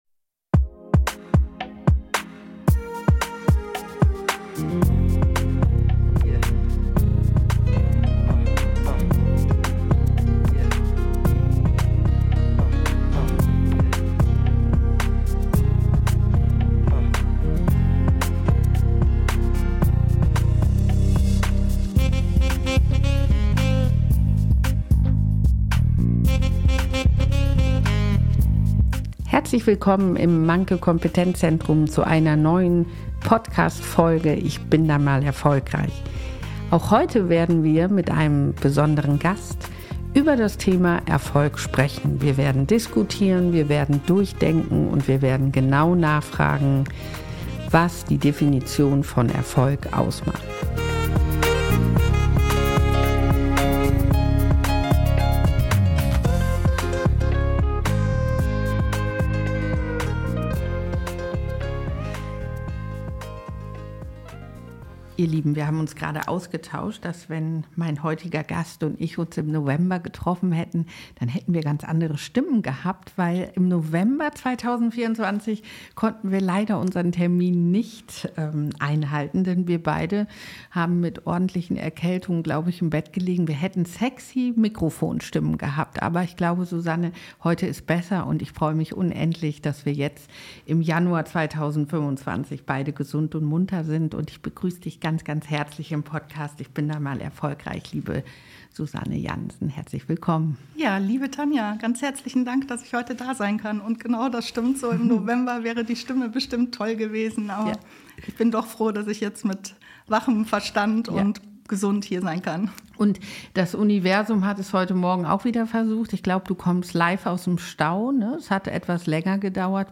Ein Gespräch über Karrierewege, den Mut zur Veränderung und die Bedeutung von Teamgeist und lebenslangem Lernen.